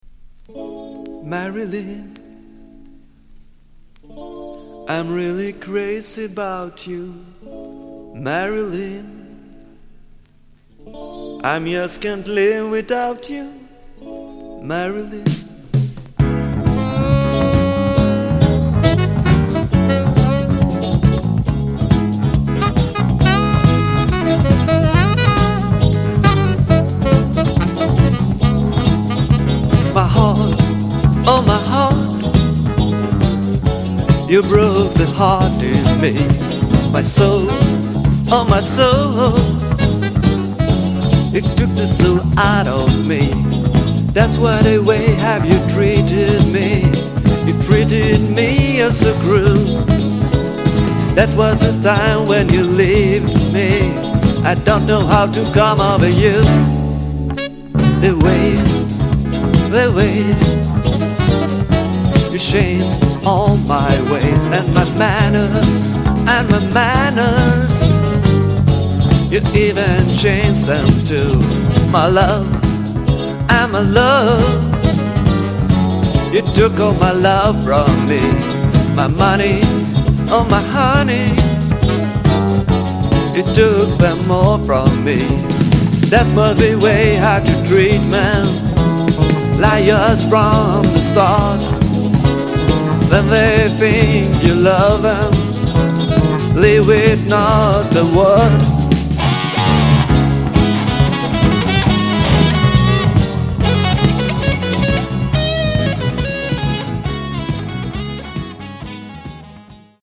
SSW / AOR sweden
アップテンポなサンバビートに、ワンホーンのジャジーなアレンジも格好良いダンサブルな一曲。